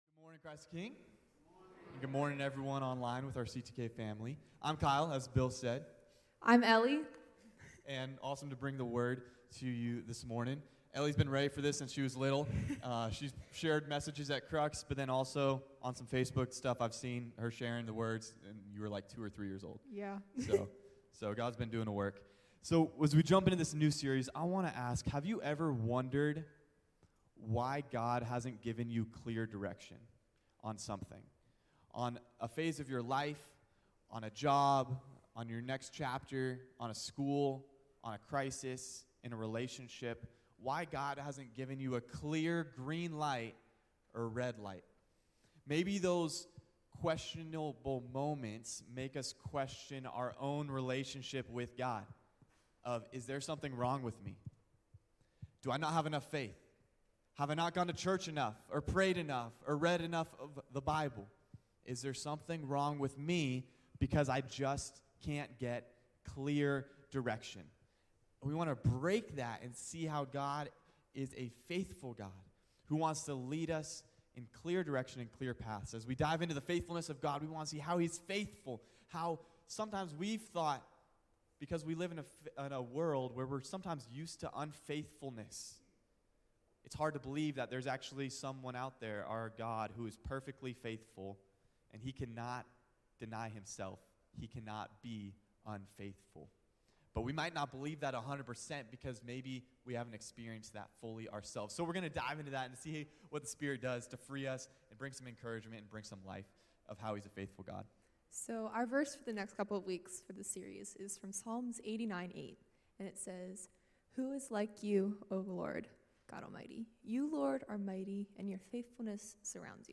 CTK-Clipped-Sermon.mp3